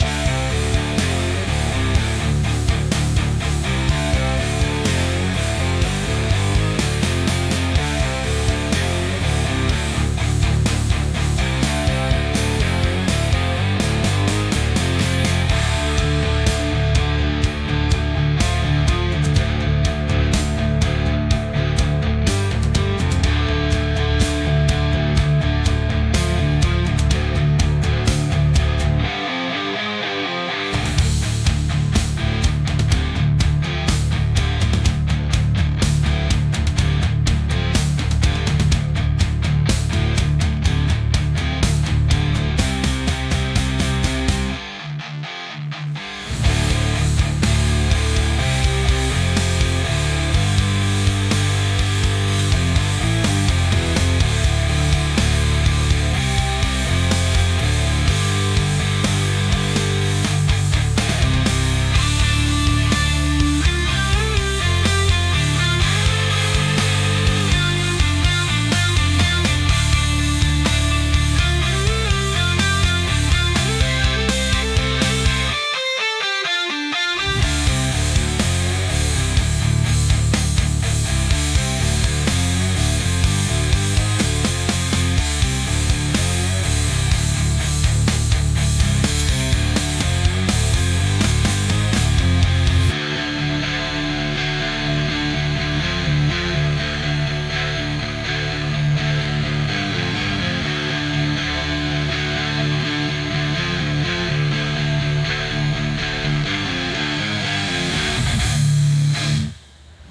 遂に出番が回ってきたときのBGM